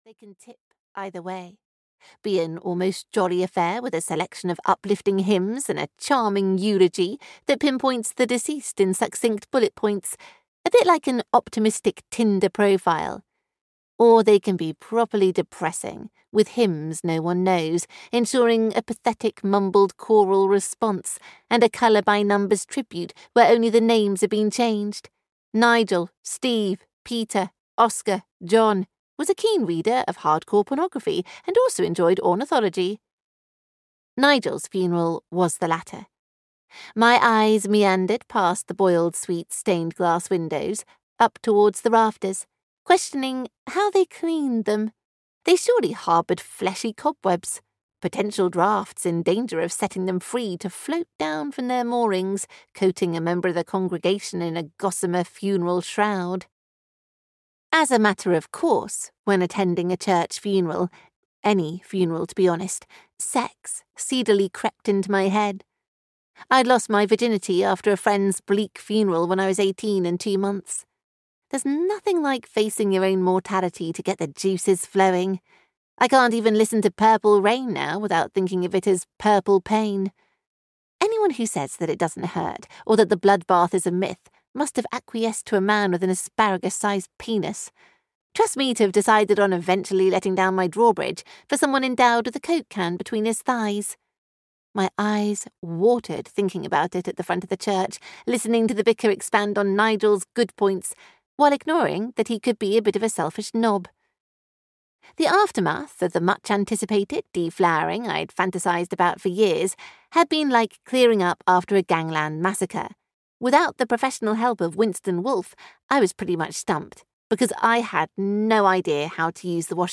The Single Mums' Secrets (EN) audiokniha
Ukázka z knihy